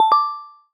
Google might let you play a subtle chime instead of a full-blown disclaimer when using Call Notes.
starting_beep_sound.ogg